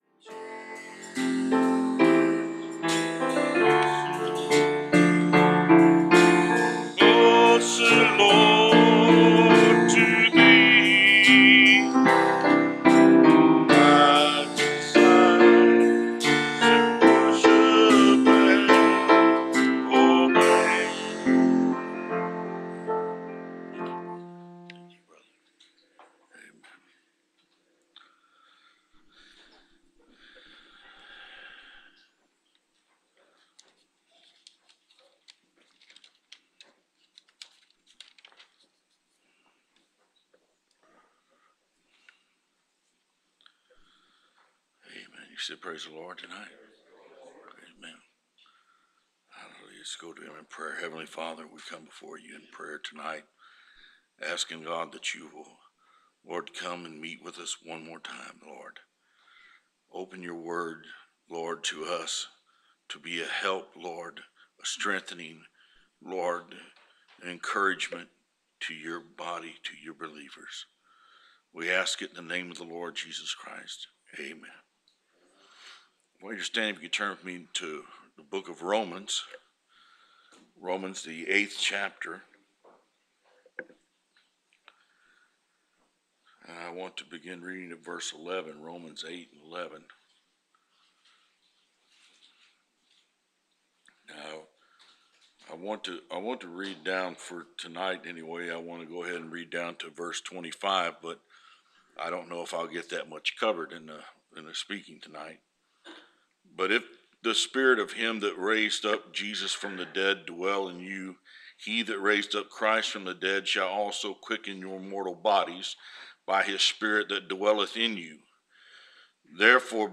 Preached October 27, 2016 Scripture List: Romans 8:11-25